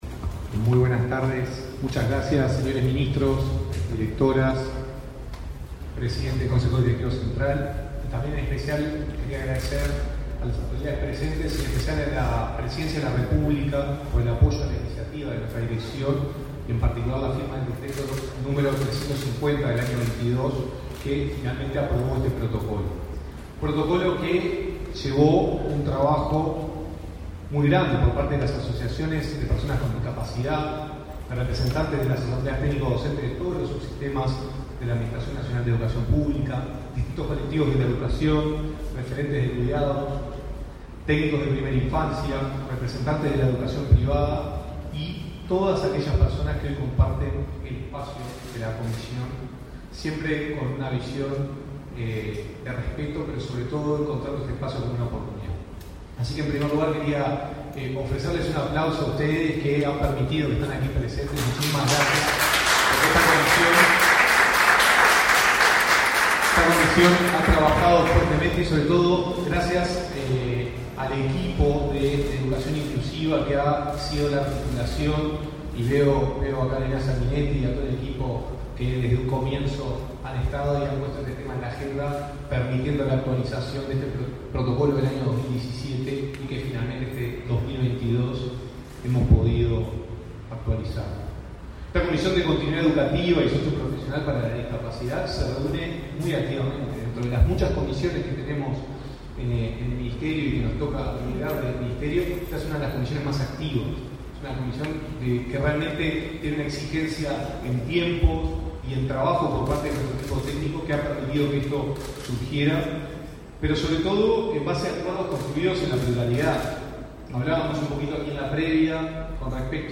Conferencia de prensa por la presentación de protocolo para garantizar educación inclusiva
Participaron el director del Ministerio de Educación y Cultura (MEC), Gonzalo Baroni; el presidente de la Administración Nacional de Educación Pública, Robert Silva, y los ministros Martín Lema, de Desarrollo Social, Pablo da Silveira, del MEC.